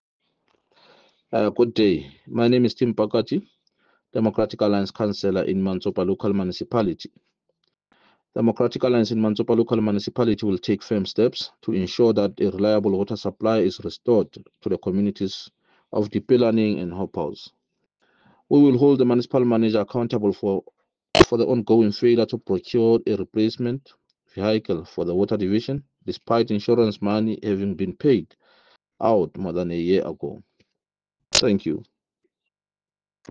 Sesotho soundbites by Cllr Tim Mpakathe